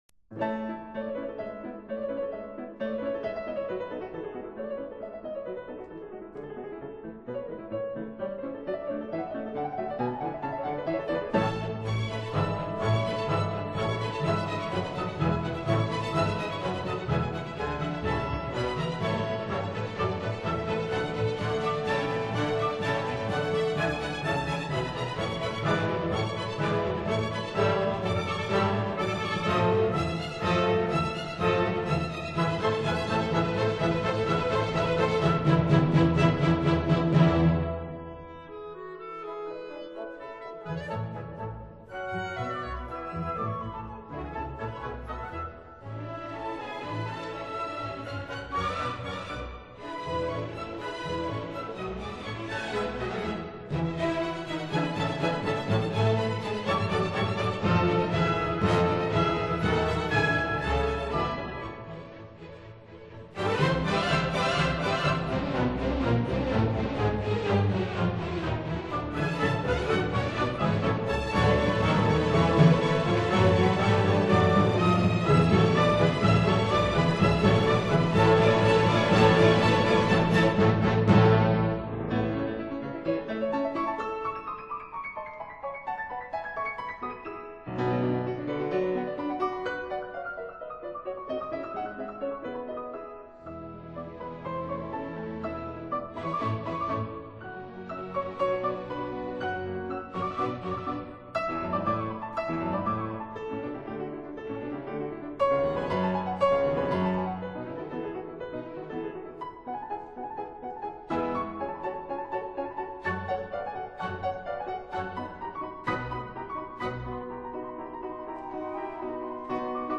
Piano Concerto No. 2 in F minor